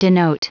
Prononciation du mot denote en anglais (fichier audio)
Prononciation du mot : denote